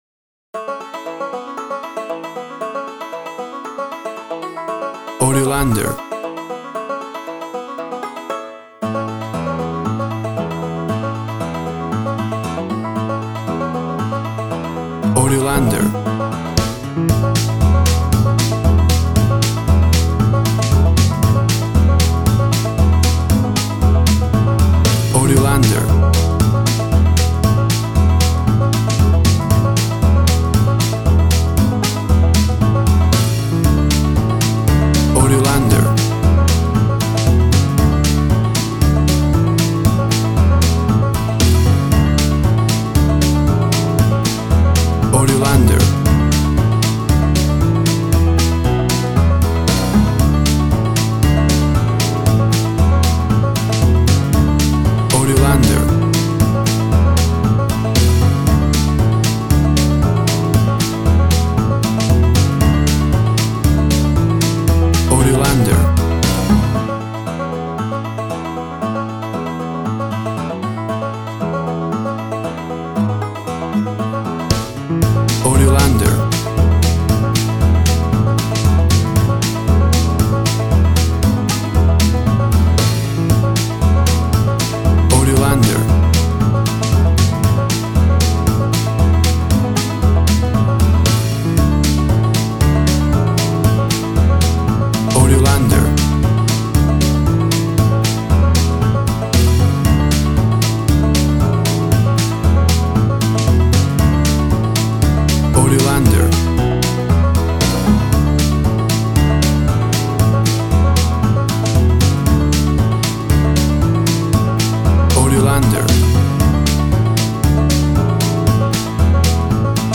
Classic country music sound.
Tempo (BPM) 115